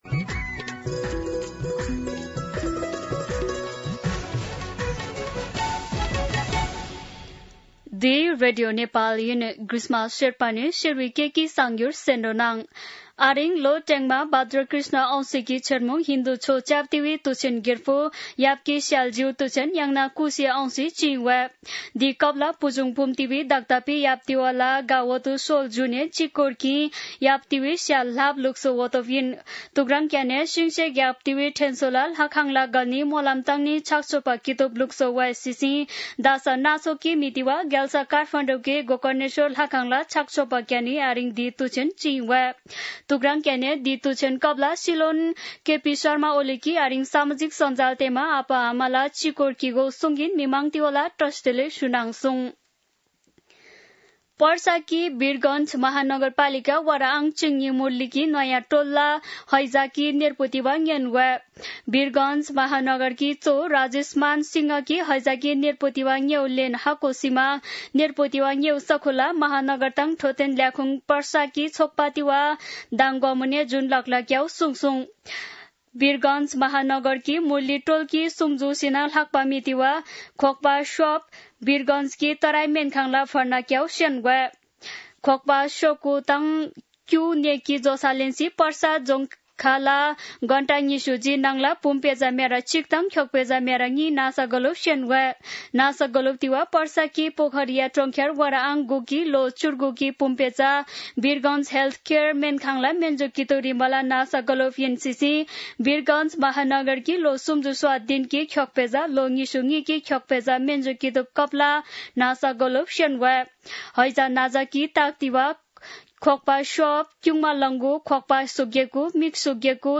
शेर्पा भाषाको समाचार : ७ भदौ , २०८२
Sherpa-News-07.mp3